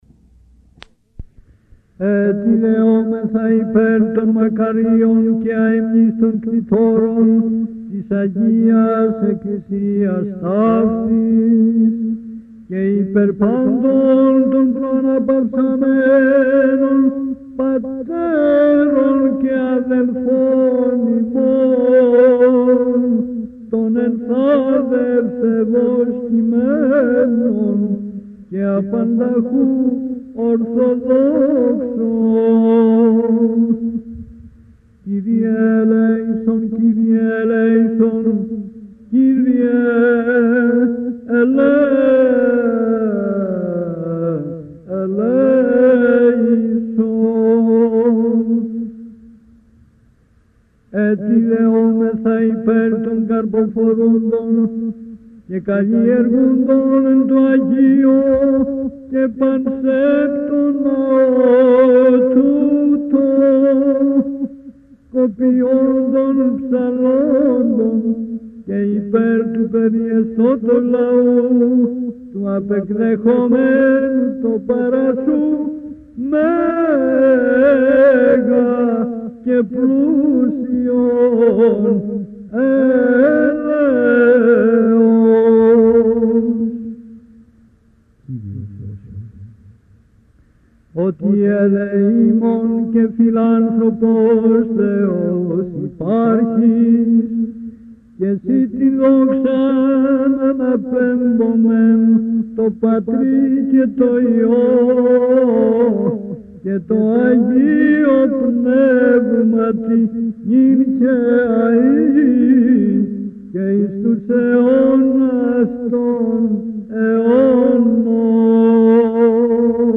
Hymns